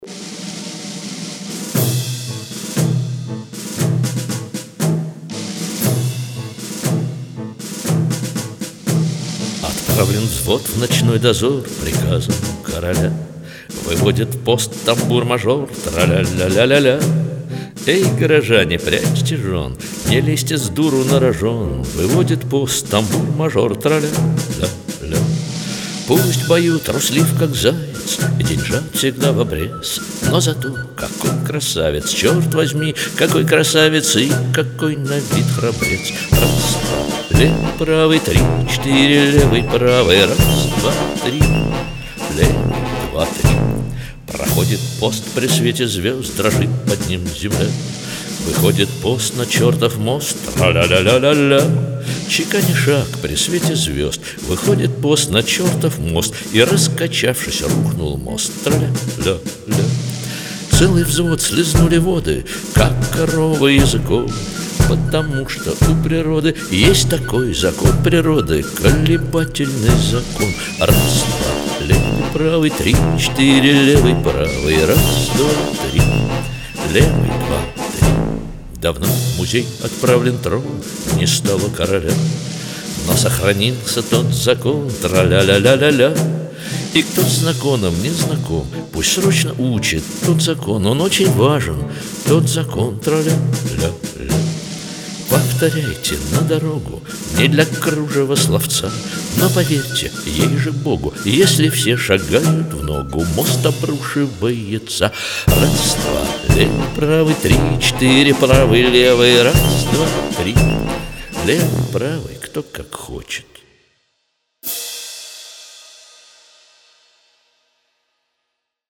Жанр: авторская песня, rock